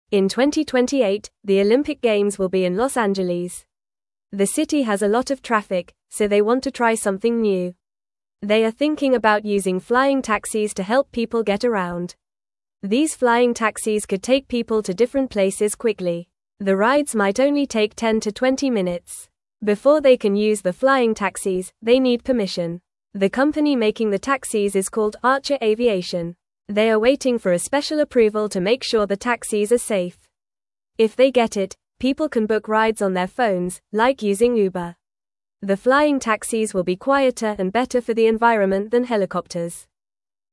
Fast
English-Newsroom-Beginner-FAST-Reading-Flying-Taxis-Could-Help-at-the-2028-Olympics.mp3